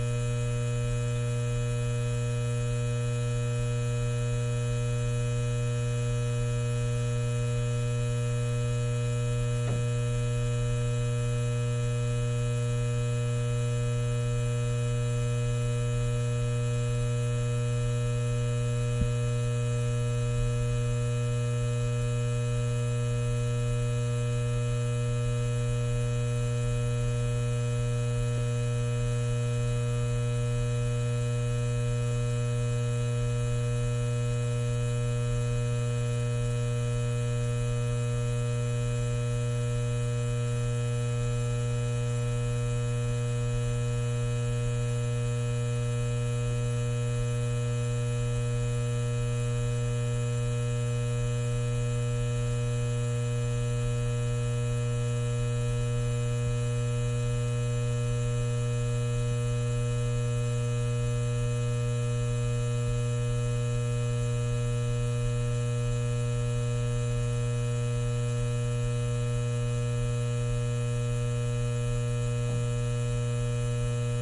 随机" 霓虹灯嗡嗡嗡立体声接近低切的味道6
描述：霓虹灯嗡嗡声嗡嗡声立体声关闭lowcut to taste6.flac